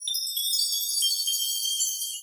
Emitter: Stars: Sound effect